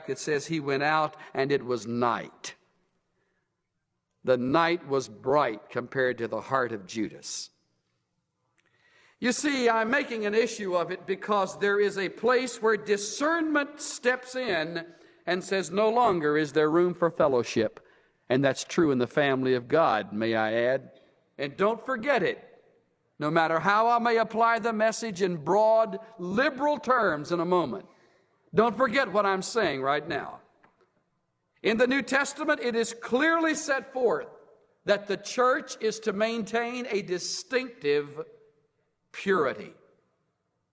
Here is Charles Swindoll speaking about Judas on the night of the Lord betrayal, as the disciples were assembled for the “Last Supper”. . . . .